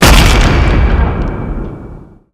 powerup_pickup_knockout_melee_hit.wav